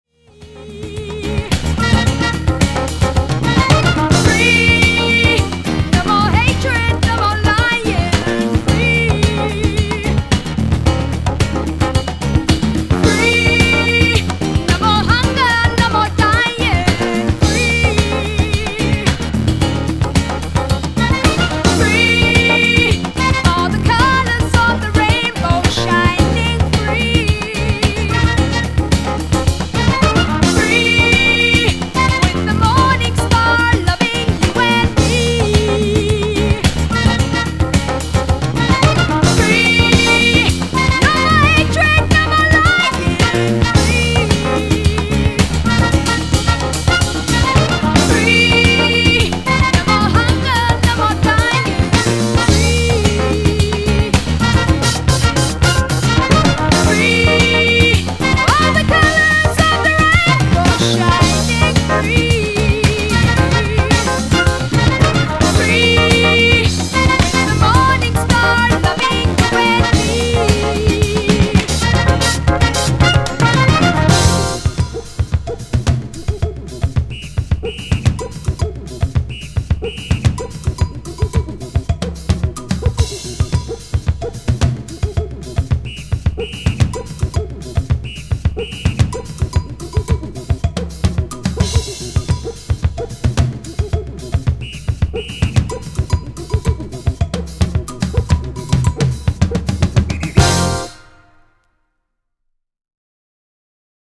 Both tracks here are classics dancefloors tunes
jazz funk or latin jazz with vocals